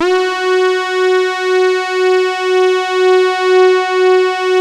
66-TARKUS F#.wav